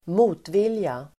Uttal: [²m'o:tvil:ja]